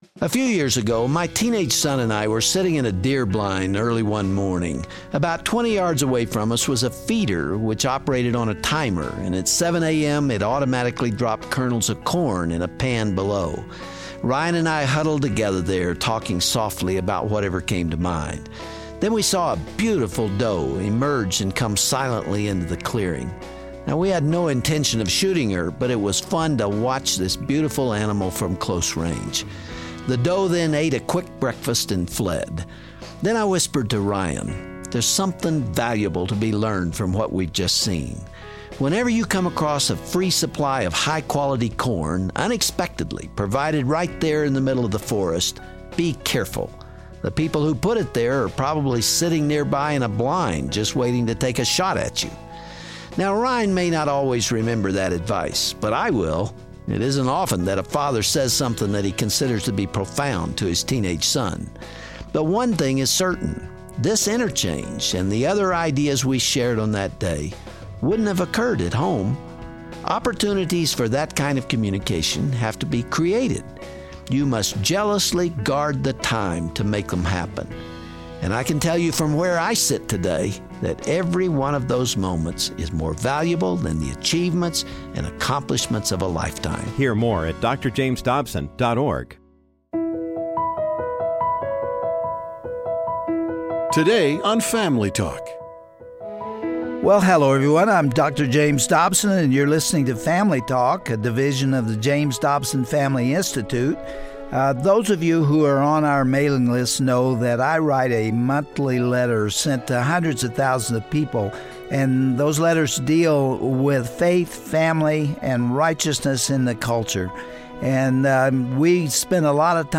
On this exclusive Family Talk broadcast, Dr. Dobson reads his September newsletter, and unpacks what he believes is the origin of these appalling acts. He explains how the breakdown of the family has negatively impacted these disturbed shooters.